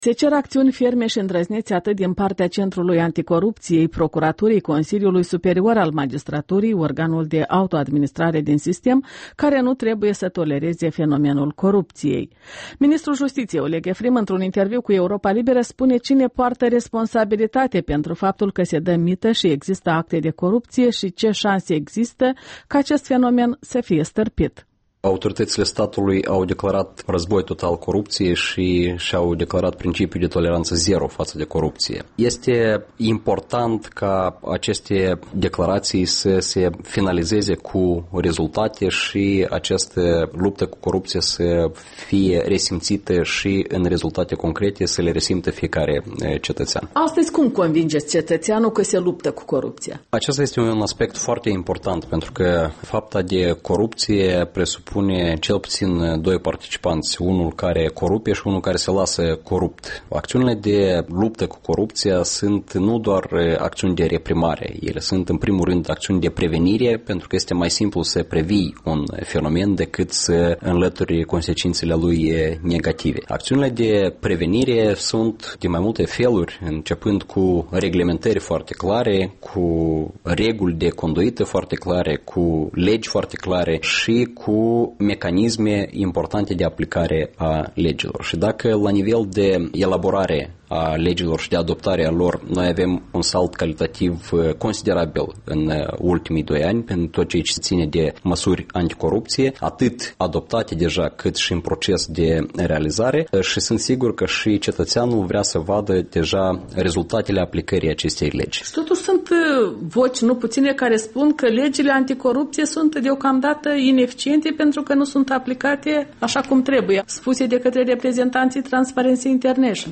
Interviul dimineții la REL: cu Oleg Efrim, ministrul Justiției, despre combaterea corupției